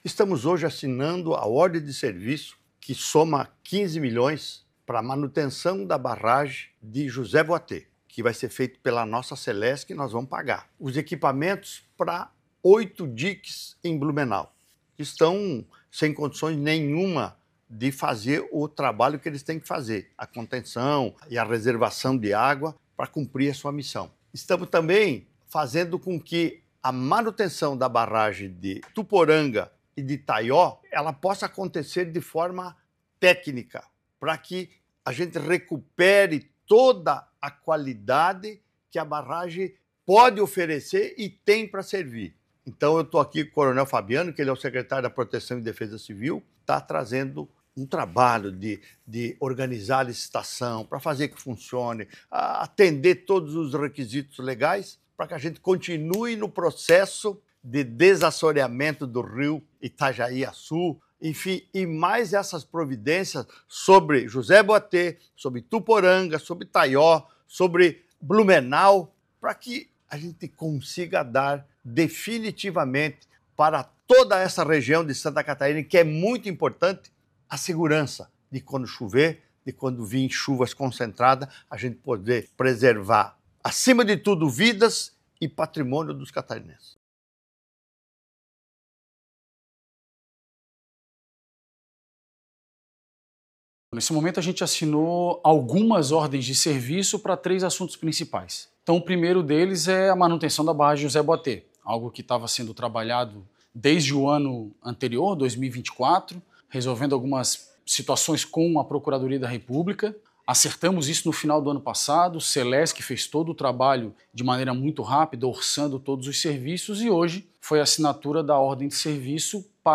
O governador Jorginho Mello destaca que as ações reforçam o compromisso do Governo do Estado com a segurança da população catarinense, em especial nas regiões mais suscetíveis a desastres naturais:
O secretário de Estado da Proteção e Defesa Civil, Fabiano de Souza, detalha as intervenções que serão realizadas: